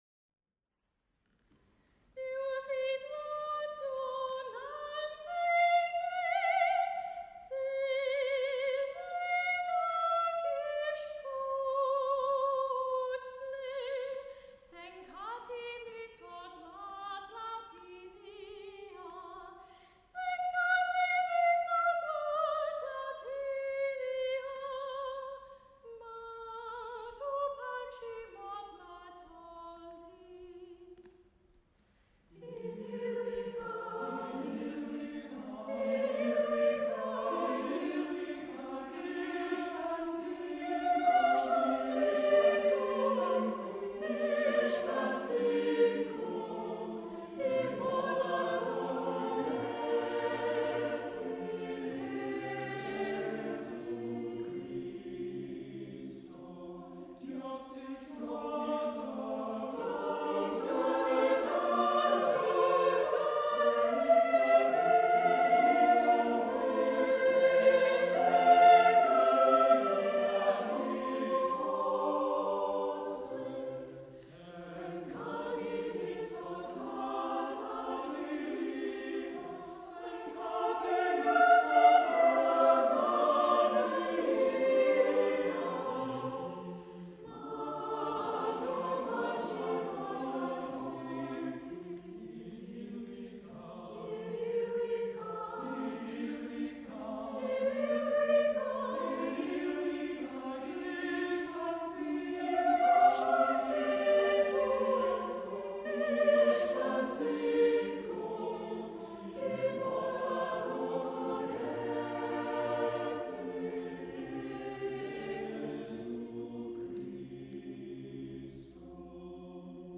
Cherwell Singers
Holy Rood Catholic Church, Abingdon Road, Oxford